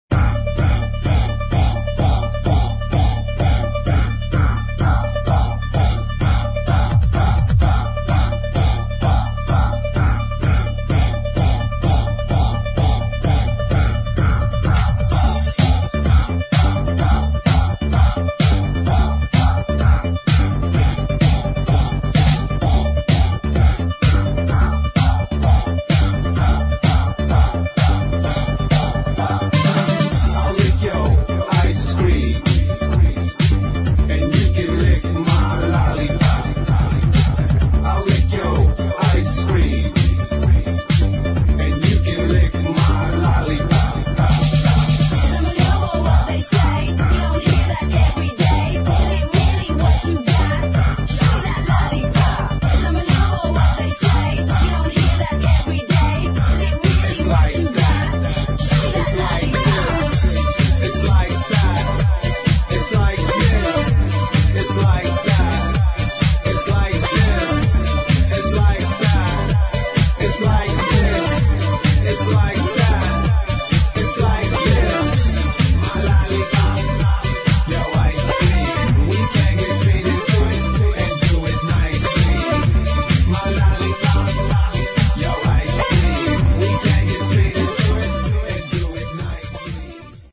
[ HOUSE / ELECTRO / ACID ]